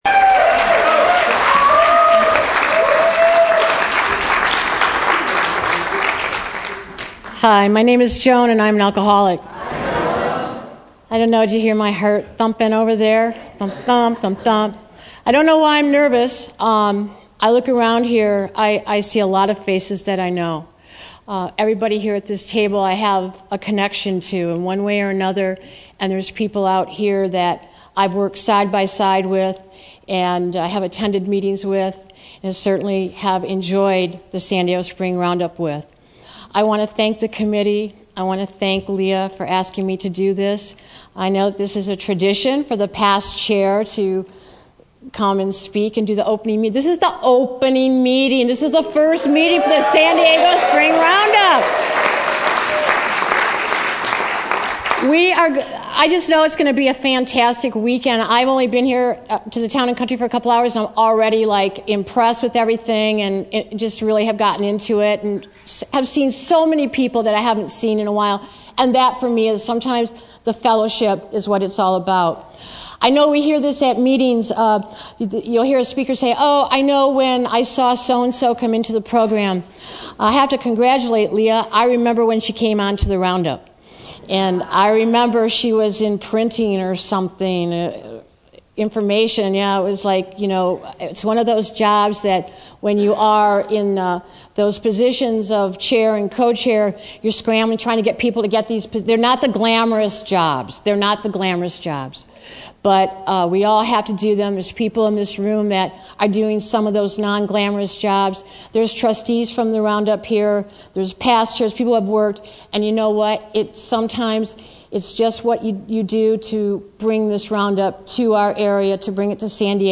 San Diego Spring Roundup 2010